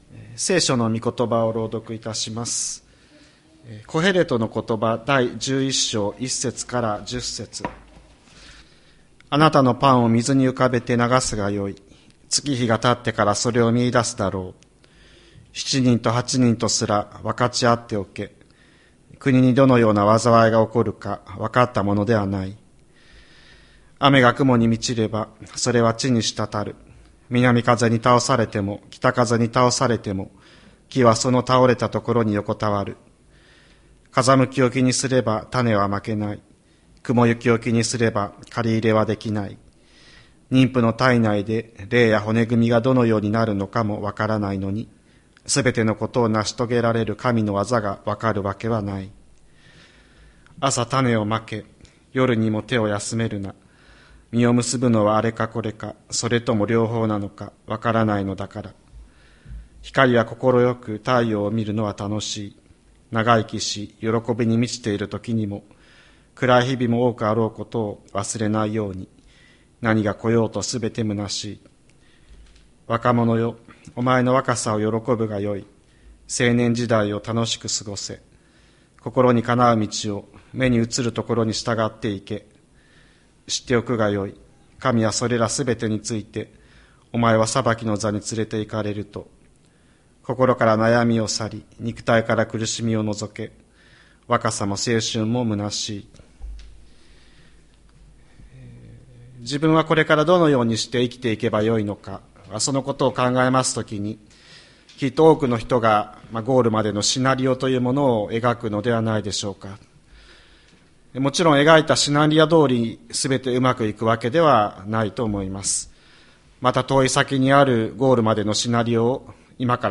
2025年02月23日朝の礼拝「先が見えないからこそ」吹田市千里山のキリスト教会
千里山教会 2025年02月23日の礼拝メッセージ。